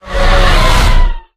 controller_whoosh.ogg